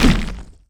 poly_shoot_stone03.wav